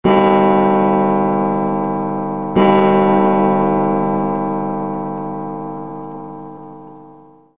古時計（2時）